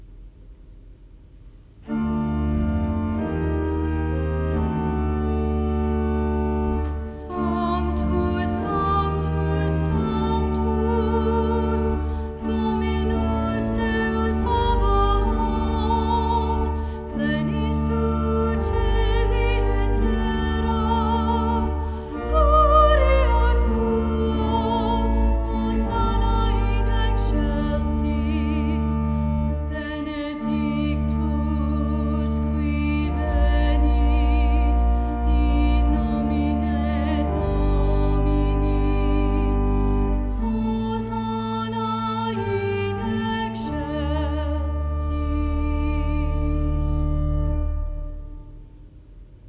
Phrygian Mass in Honor of St. David the King (congregational Mass setting)
Solo (David Mass recordings)
solo-sanctus.m4a